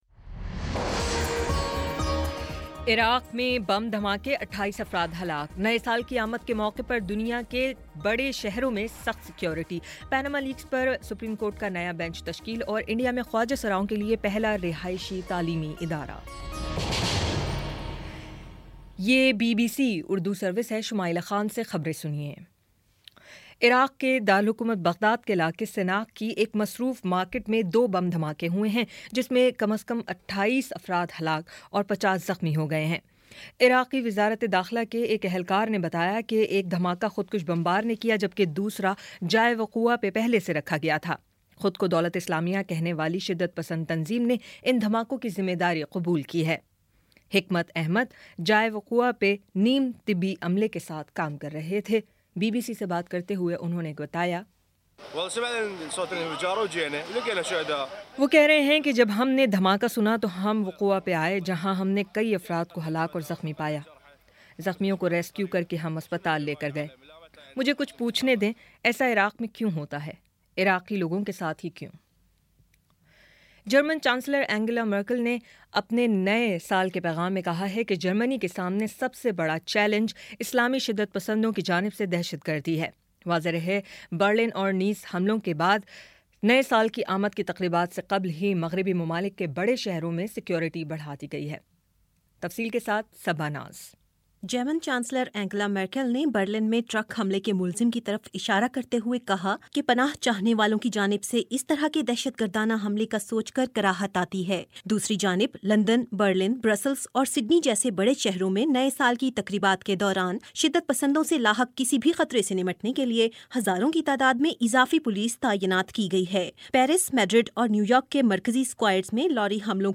دسمبر 31 : شام پانچ بجے کا نیوز بُلیٹن